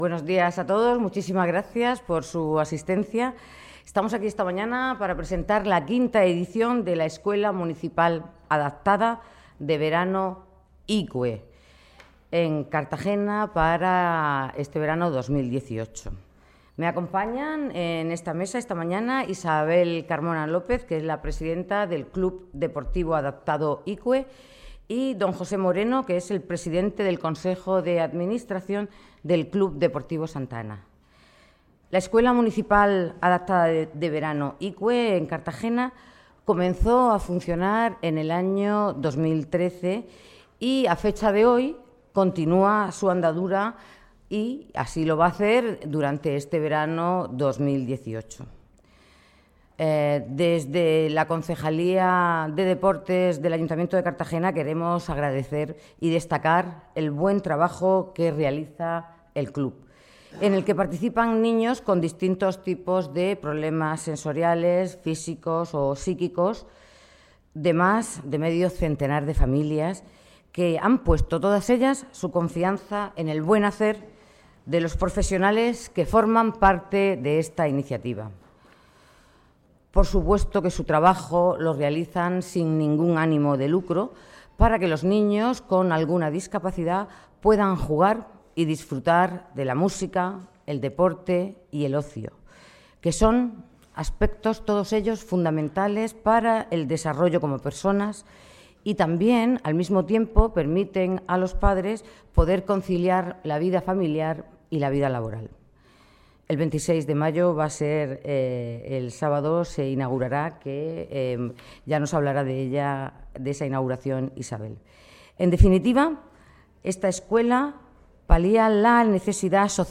Audio: Presentaci�n de la Escuela de Verano Icue (MP3 - 7,87 MB)